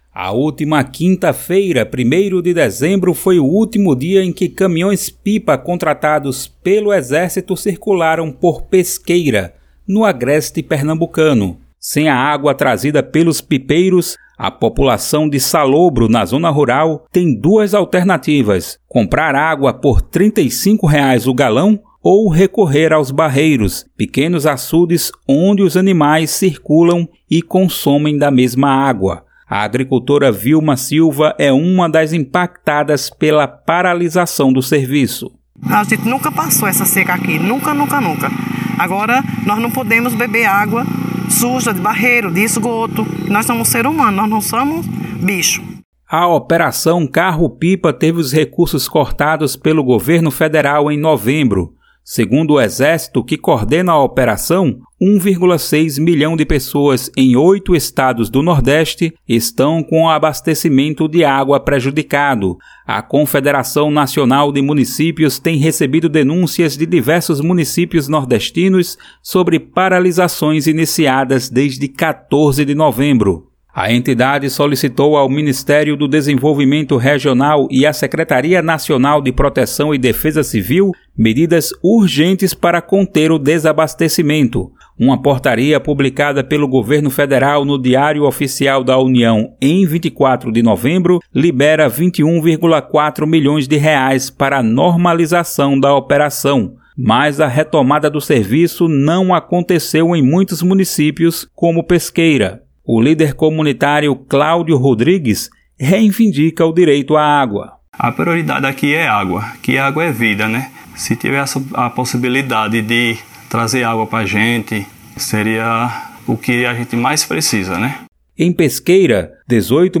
veja em vídeo Governo Bolsonaro interrompe Operação Carro-Pipa e deixa famílias sem água em Pernambuco Reportagem do "Central do Brasil" visitou um dos locais onde população deixou de ter acesso a água limpa